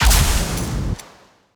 Rocket Launcher
GUNArtl_Rocket Launcher Fire_02_SFRMS_SCIWPNS.wav